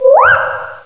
pokeemerald / sound / direct_sound_samples / cries / minccino.aif